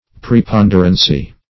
\Pre*pon"der*an*cy\, n. [Cf. F. pr['e]pond['e]rance.]